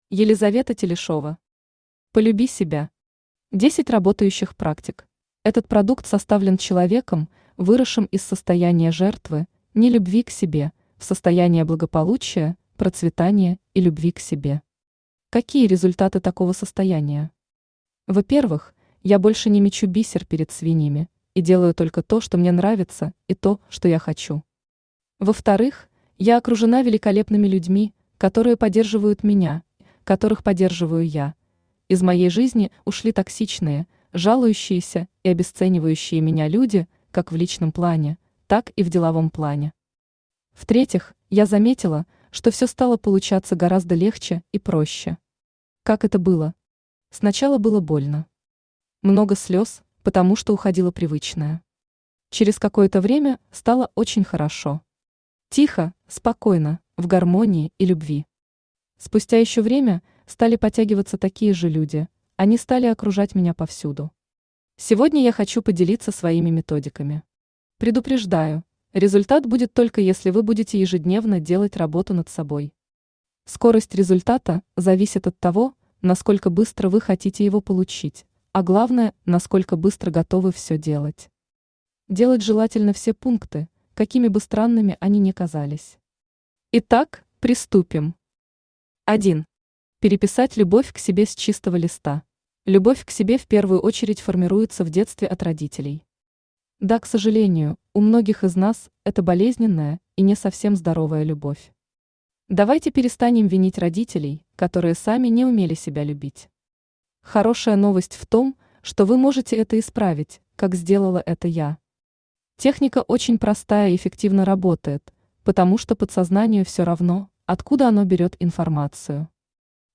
Аудиокнига Полюби себя. 10 работающих практик | Библиотека аудиокниг
Aудиокнига Полюби себя. 10 работающих практик Автор Елизавета Телешева Читает аудиокнигу Авточтец ЛитРес.